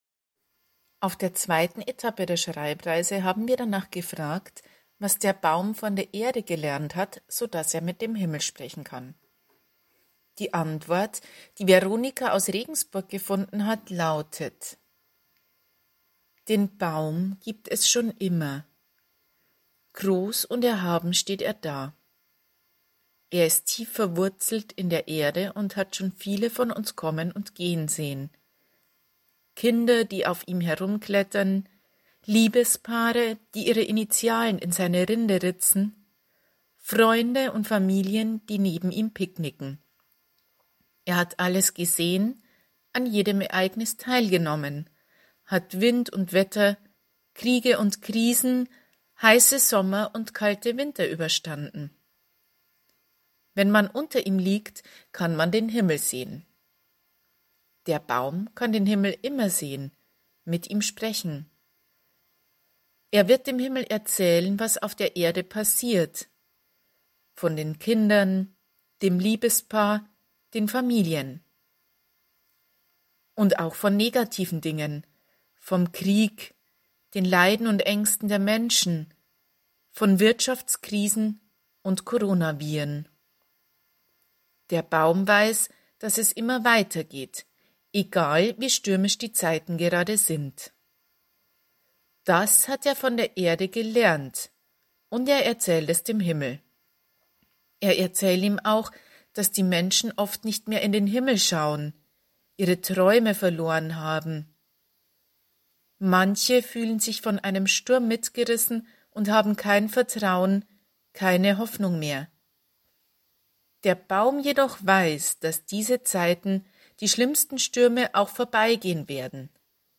Schreibreise Podcast #2: vorgelesen